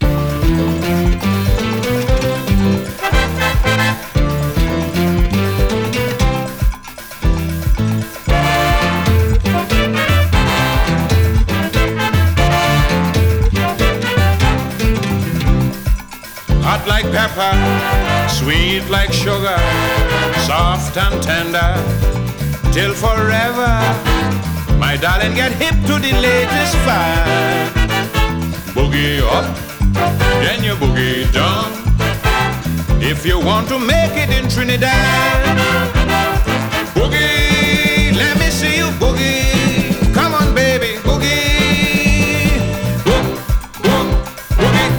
Calypso, Soca, Disco, Soul　USA　12inchレコード　33rpm　Stereo